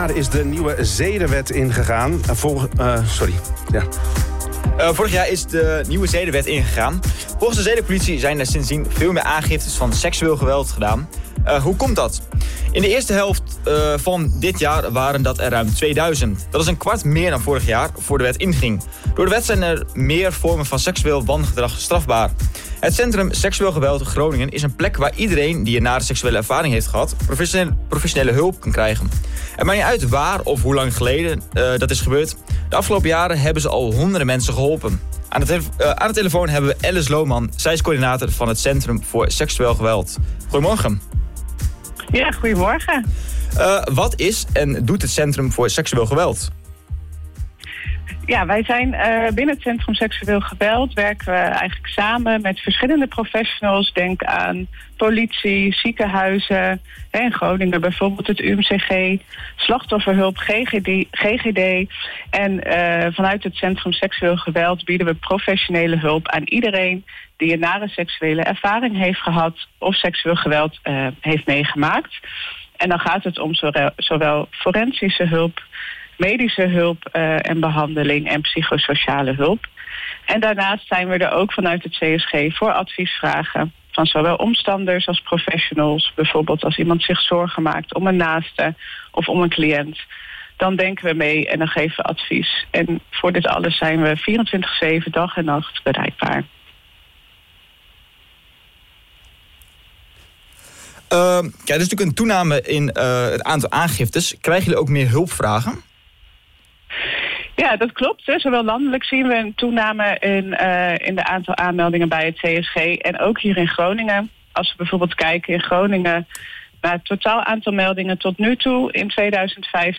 Interview-Aantal-aangiftes-stijgt-sinds-invoering-zedenwet.mp3